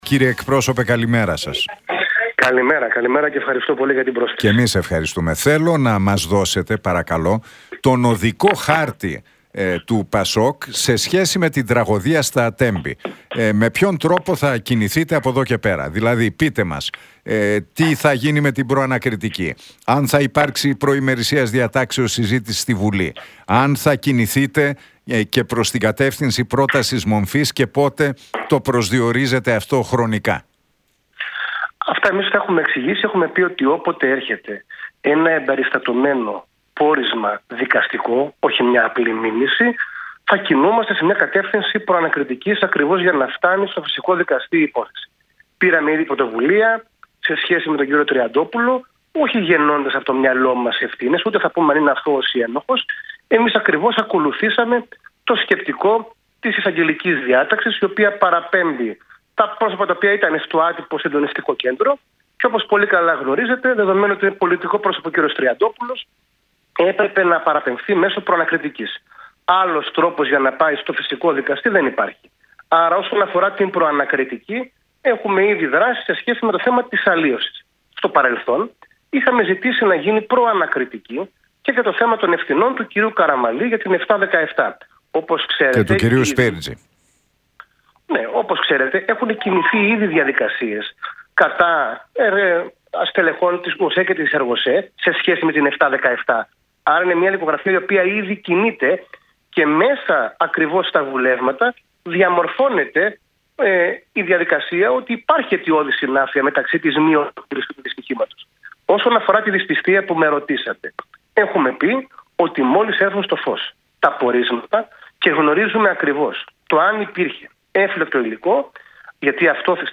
Για την υπόθεση των Τεμπών, την Προανακριτική Επιτροπή για τον Χρήστο Τριαντόπουλο και το ενδεχόμενο κατάθεσης πρότασης μομφής κατά της κυβέρνησης μίλησε ο εκπρόσωπος Τύπου του ΠΑΣΟΚ, Κώστας Τσουκαλάς στον Νίκο Χατζηνικολάου από την συχνότητα του Realfm 97,8.